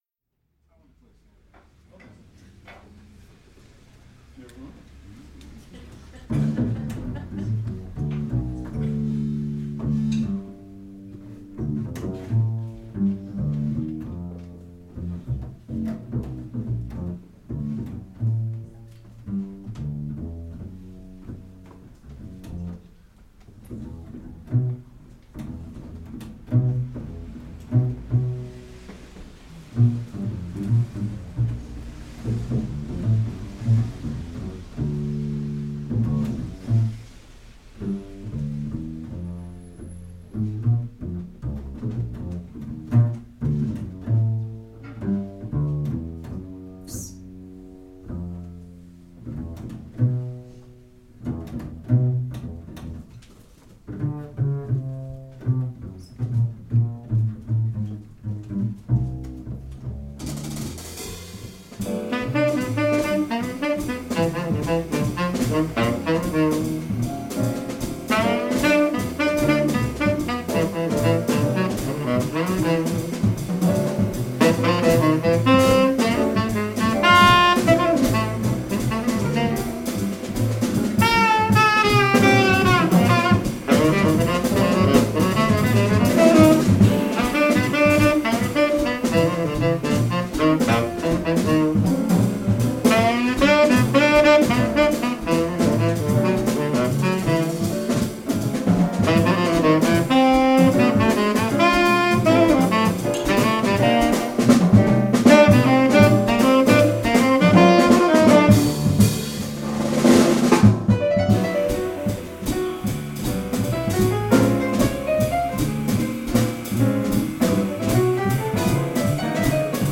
Bass
Piano
Drums
Tenor
Special Guest Drummer